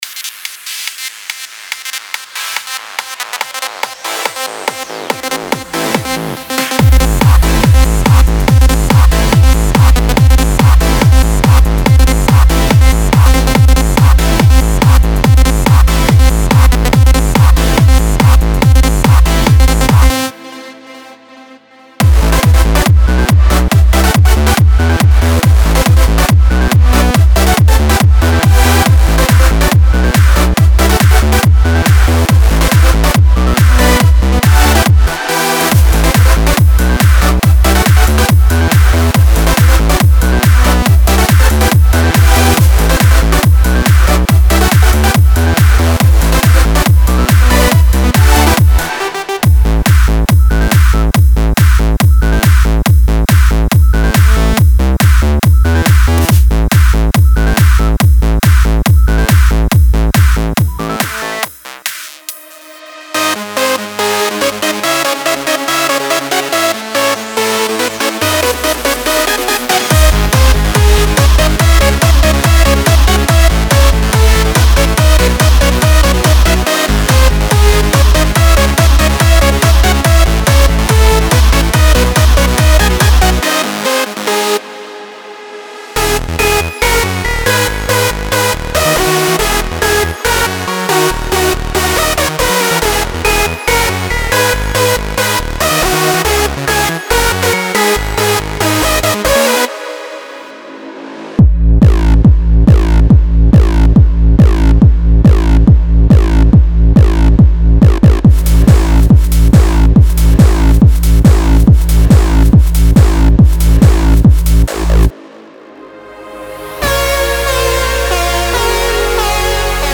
Eurodance Melodic Techno